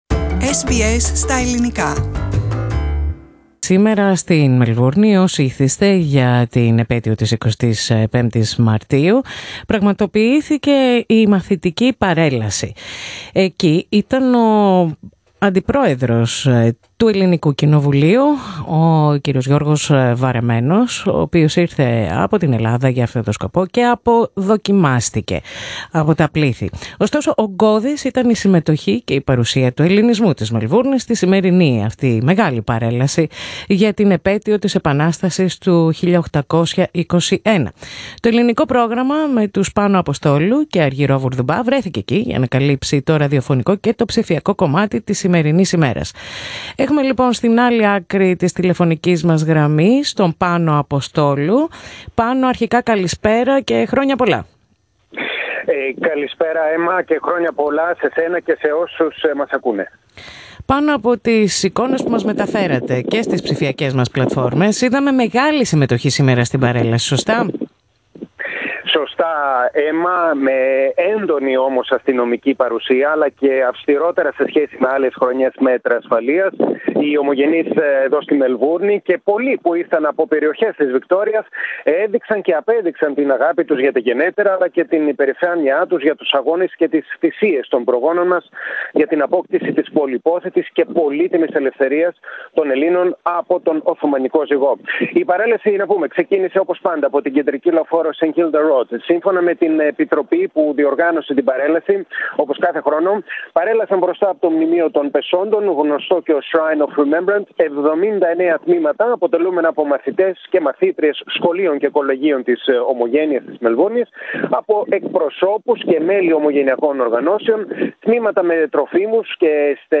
ΑΚΟΥΣΤΕ: Την ηχητική μετάδοση από τον εορτασμό της Εθνικής Επετείου στην Μελβούρνη